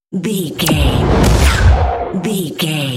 Sci fi shot whoosh to hit
Sound Effects
futuristic
intense
woosh to hit